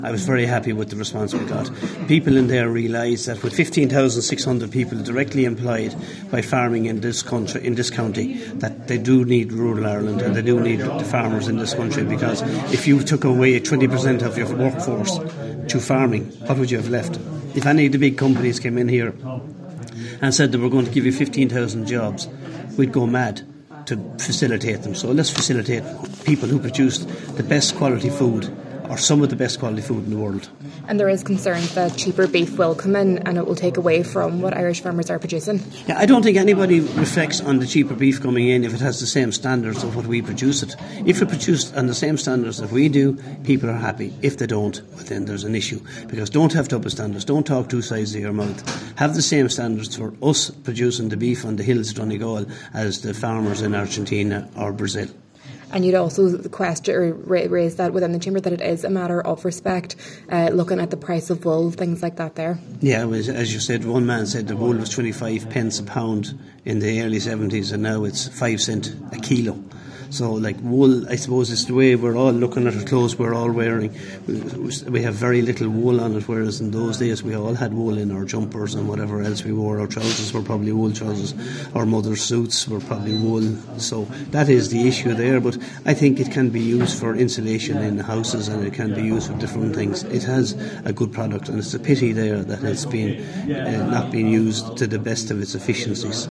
The IFA today made representations to a Plenary Meeting of Donegal County Council.